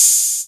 VEC3 Cymbals Ride 16.wav